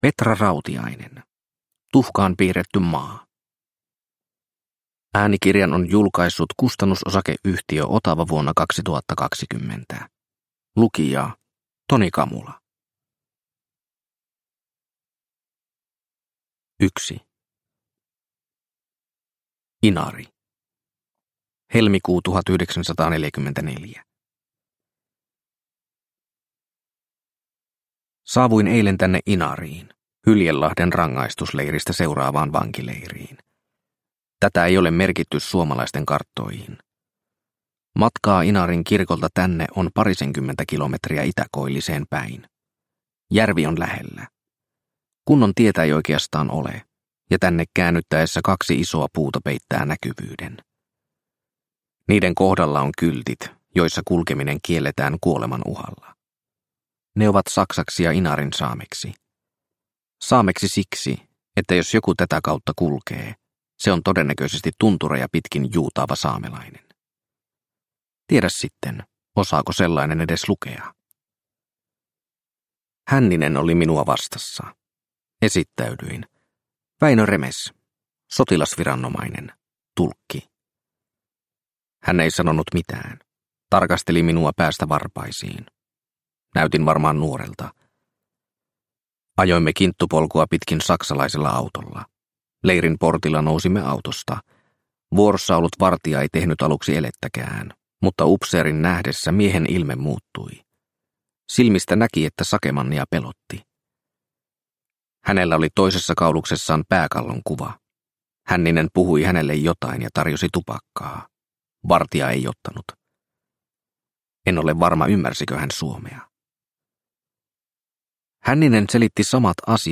Tuhkaan piirretty maa – Ljudbok – Laddas ner